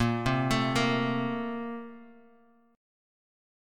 BbM7sus2 Chord